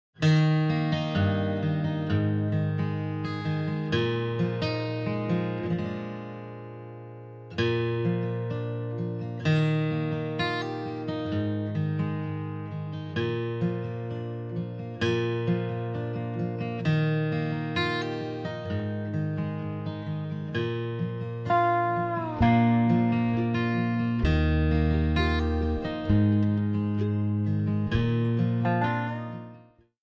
Backing track files: Country (2471)
Buy With Backing Vocals.